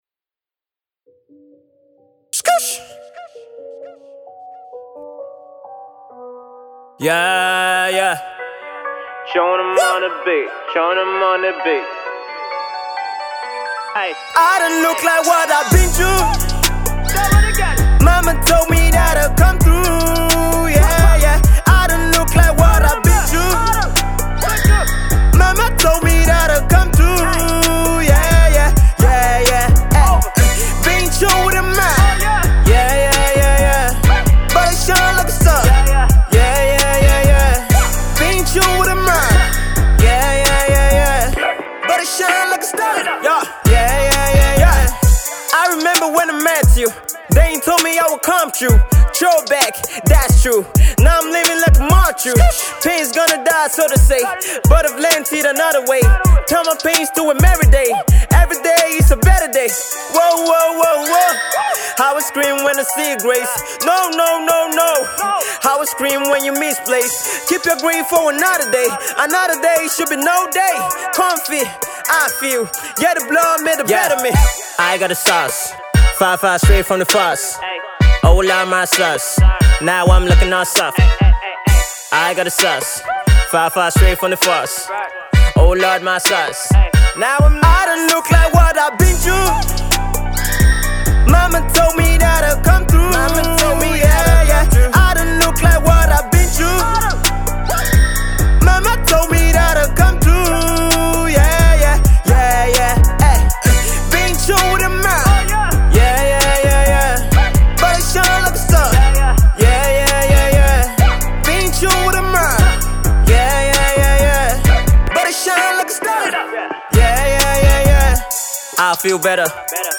gospel hip hop